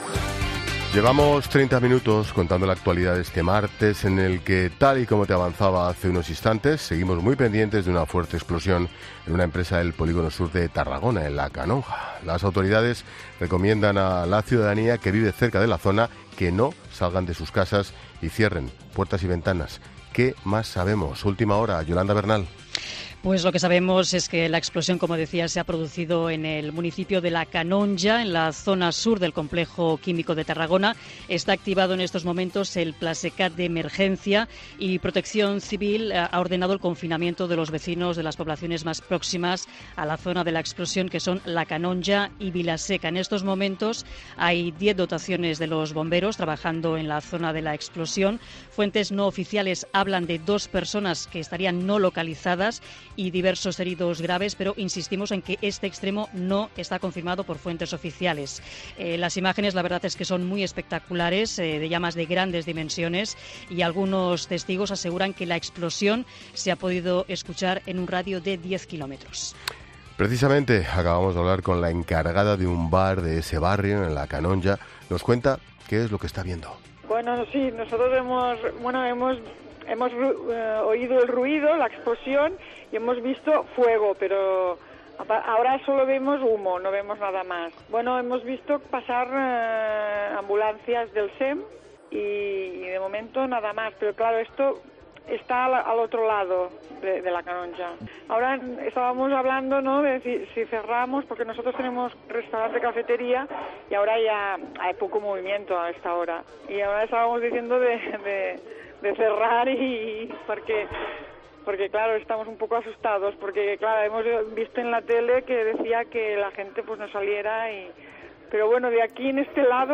La Linterna entrevista a la encargada de un bar cerca de la explosión de Canonja (Tarragona)